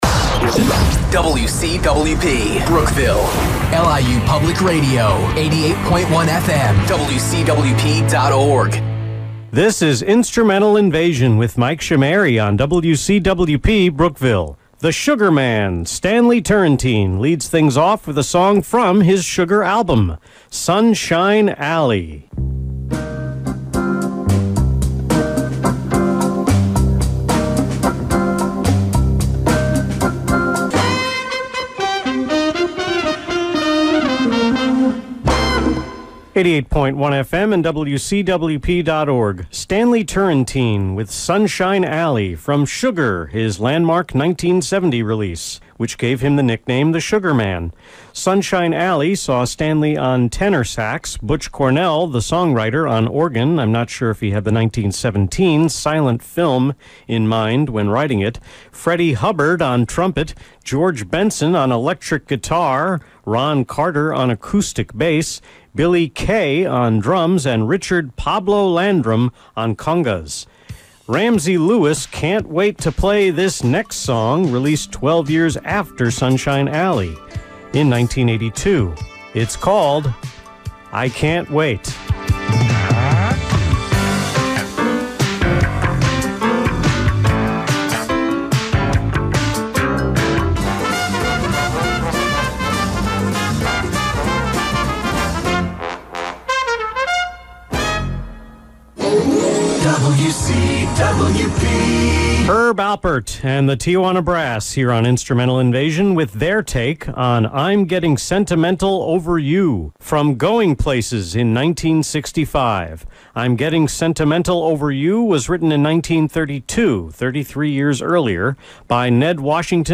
The May 19 Instrumental Invasion on WCWP was recorded entirely on my laptop through an Apogee MiC 96k.